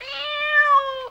the cat's meow (which is 1.2 seconds long), you might define the following sequences:
meow.aiff